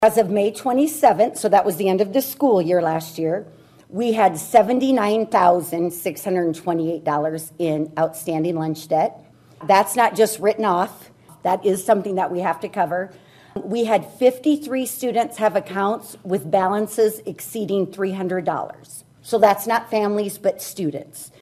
EXPLAINED THE ISSUE TO SCHOOL BOARD MEMBERS MONDAY NIGHT: